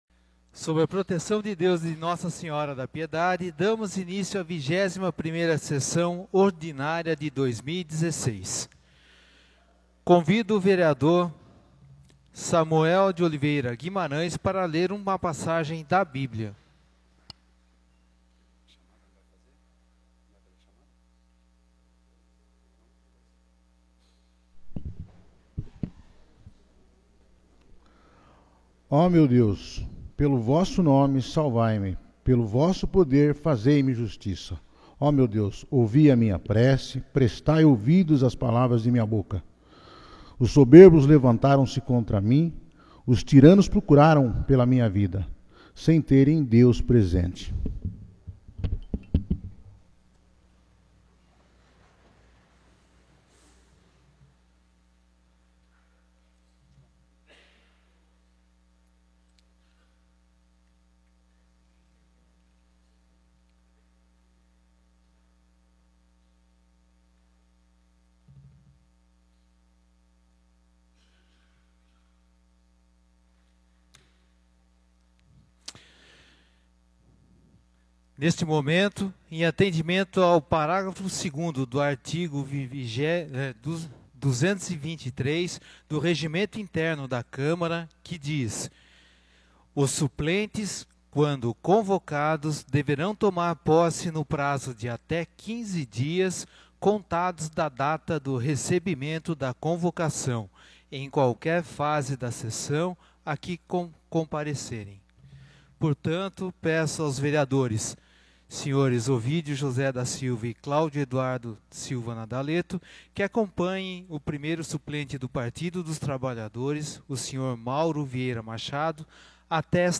21ª Sessão Ordinária de 2016